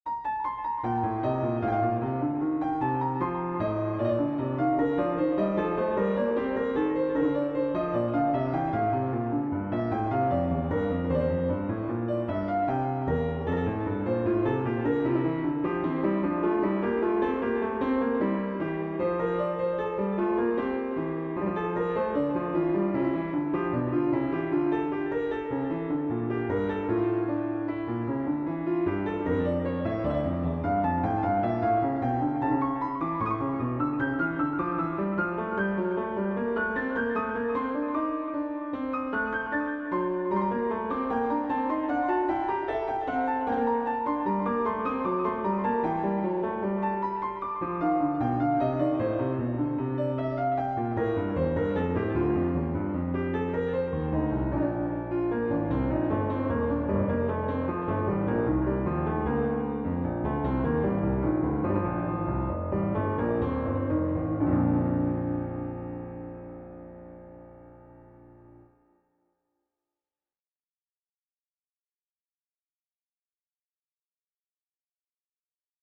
Microtonal Invention No. 3 in Bb - Piano Music, Solo Keyboard - Young Composers Music Forum
I have yet another microtonal invention for you!